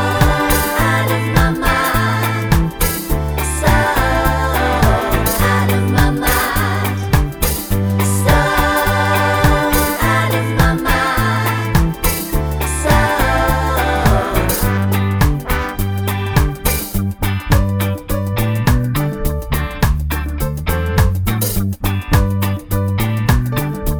no Backing Vocals Reggae 3:20 Buy £1.50